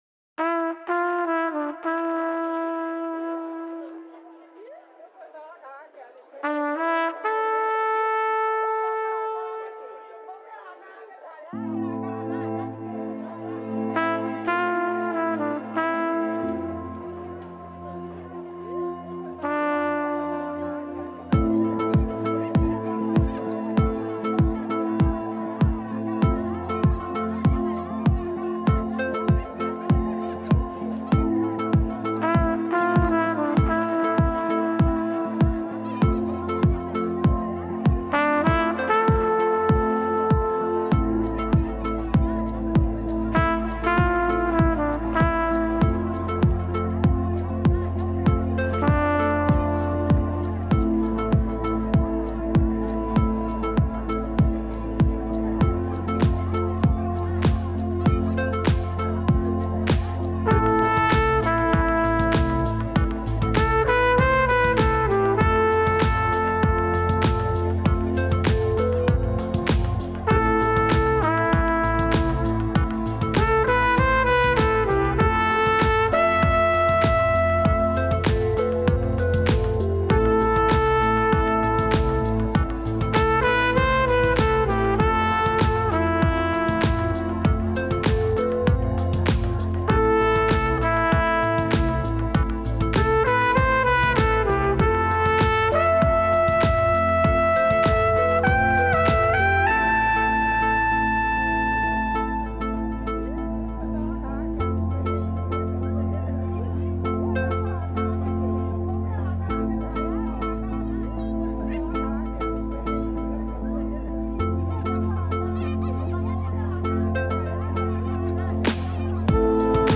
Genre: Trance, House, Dance, Chill Trance.